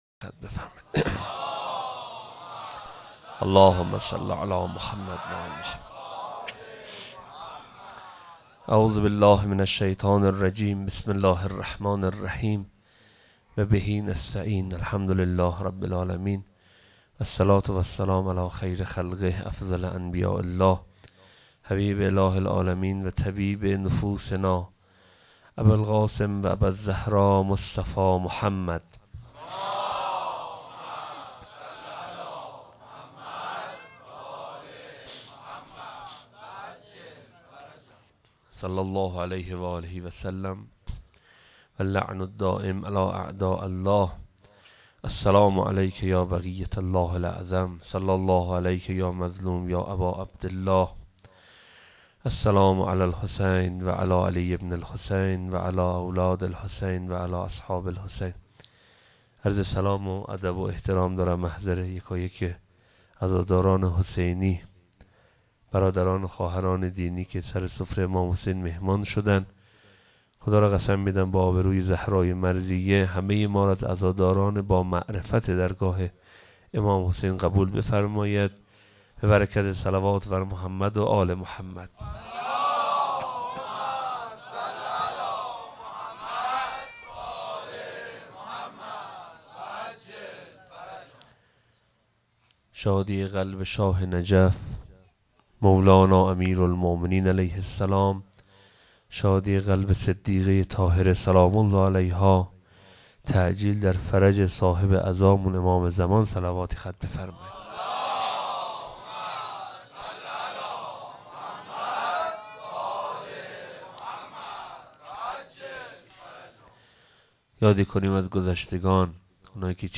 سخنرانی.wma